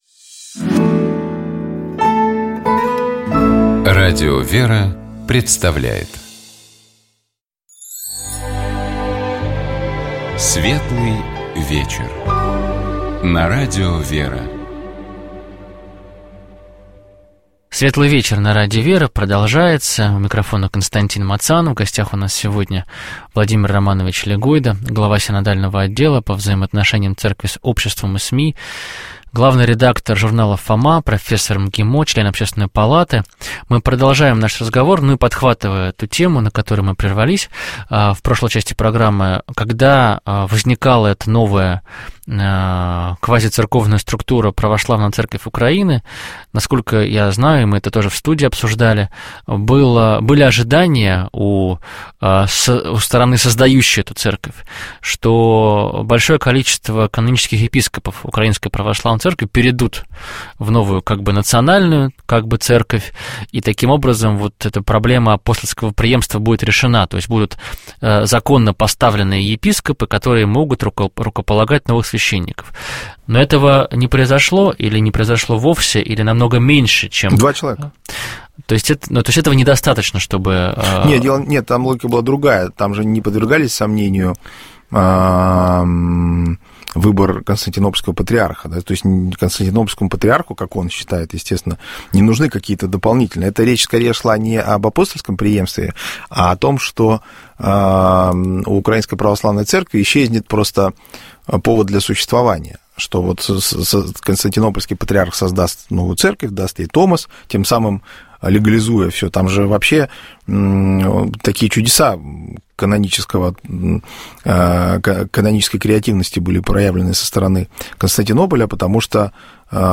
Гость программы — Владимир Легойда, председатель Синодального отдела по взаимоотношениям Церкви с обществом и СМИ, член Общественной палаты РФ.